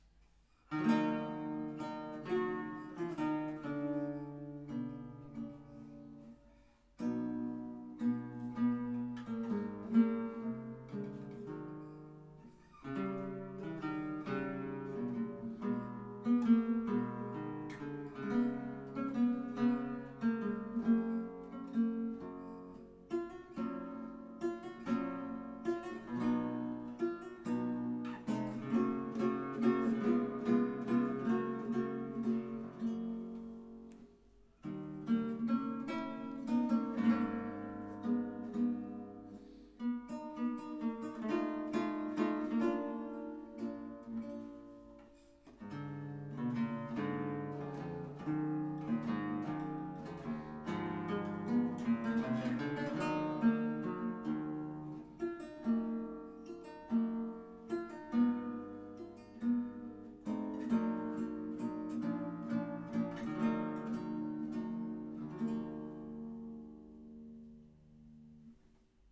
La particolare forma conferisce allo strumento, oltre ad una palese scomodità esecutiva, una caratteristica emissione sonora che la differenzia notevolmente dalle coeve chitarre tradizionali.
Lyra-Chitarra, Blaisè, Parigi 1850 ca